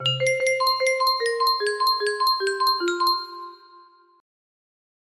La Campanella music box melody